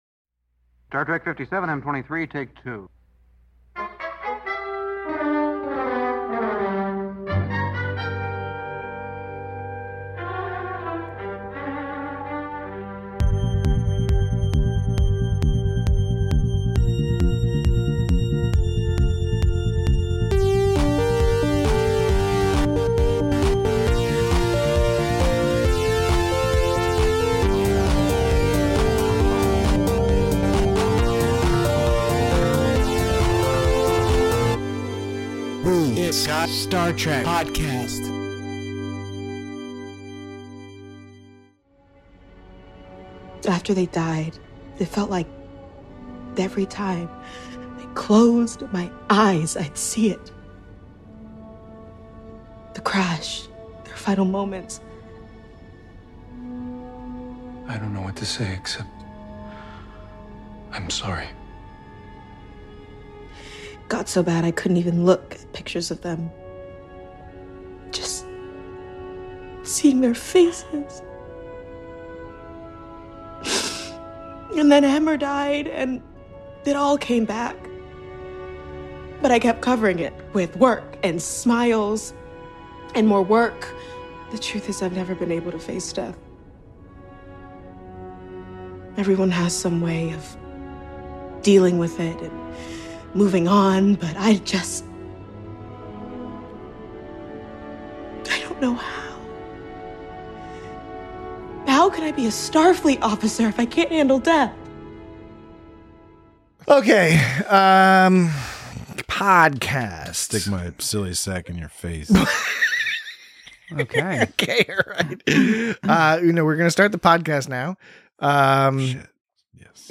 Next episode: We continue our weekly coverage of Star Trek: Strange New Worlds' second season with the seventh episode, "Those Old Scientists" Note that this and all episodes of the It’s Got Star Trek podcast contain explicit language and, frankly, an unnecessary amount of offensive content, so the show is intended only for adults and really&nbsp